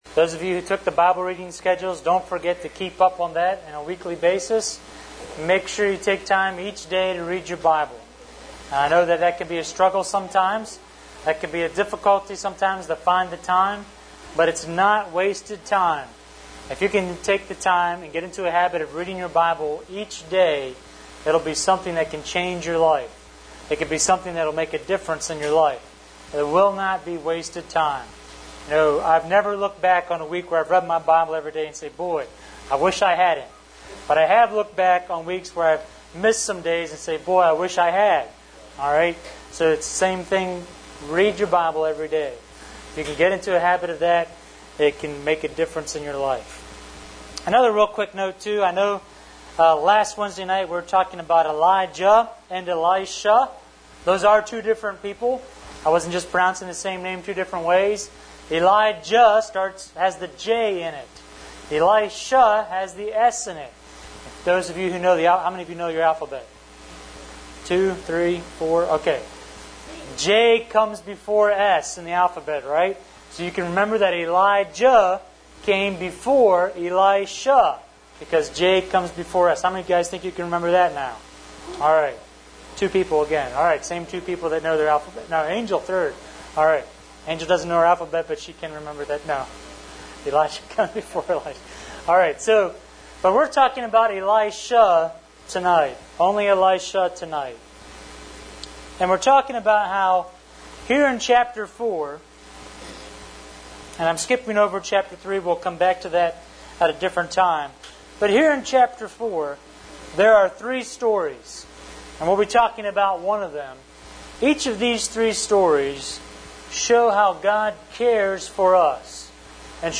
Here you will find audio from the various services.